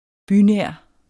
Udtale [ ˈby- ]